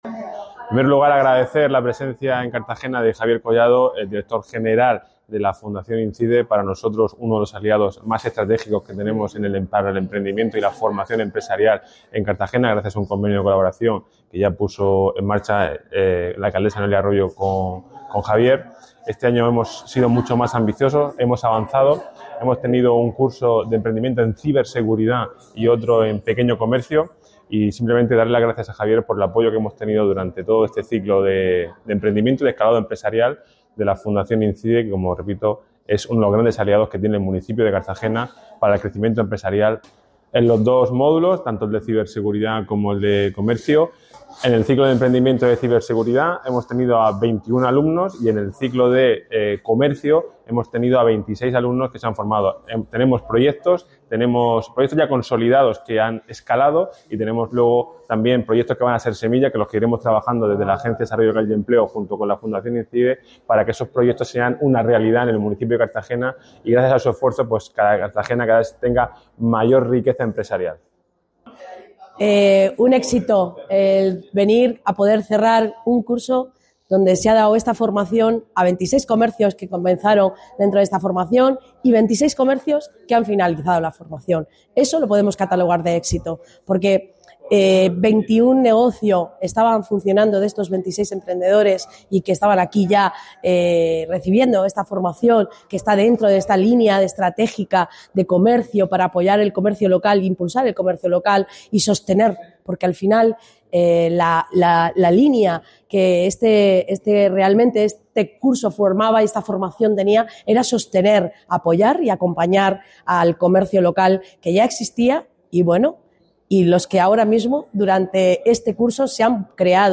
Declaraciones Clausura cursos ADLE